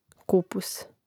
Rastavljanje na slogove: ku-pus